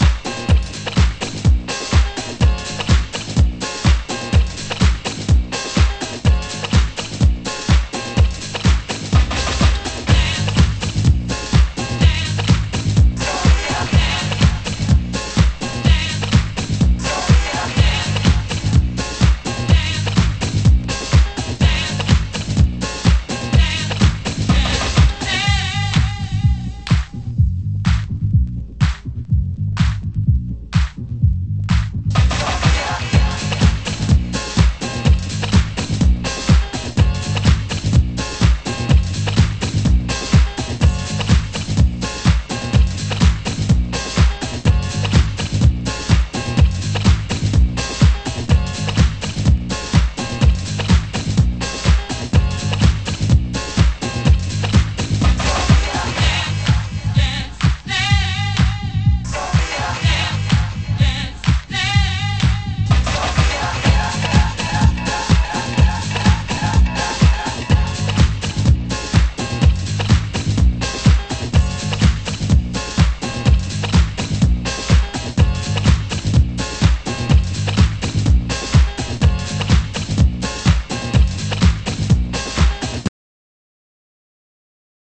盤質：少しチリパチノイズ有/かなりの重量盤